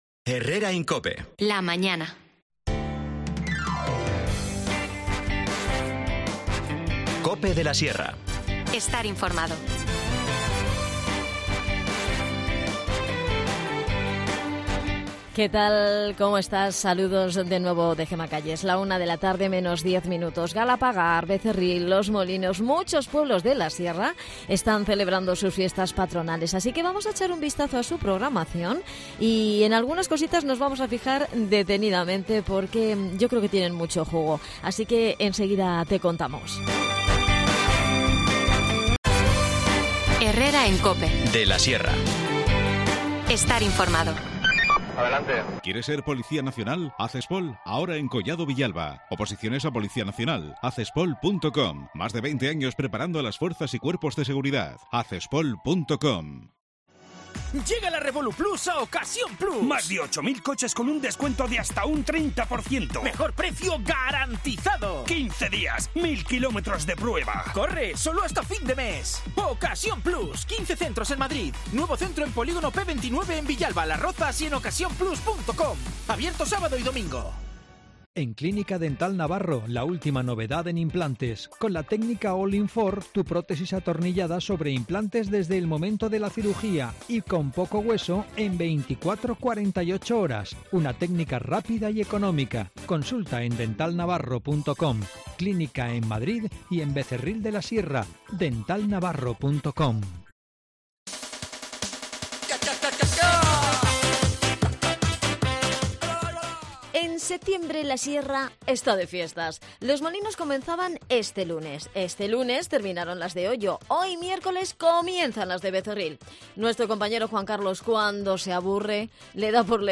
Los analizamos en clave de humor.
Las desconexiones locales son espacios de 10 minutos de duración que se emiten en COPE, de lunes a viernes.